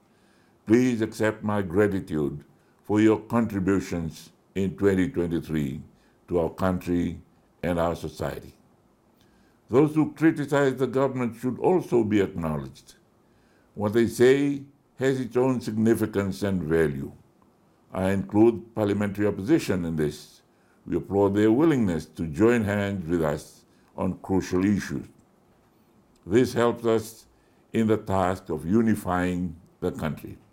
In his Christmas message to the nation, Rabuka made special mention of the health officials, peacekeepers, diplomats, military and police personnel and the members of the Fijian diaspora.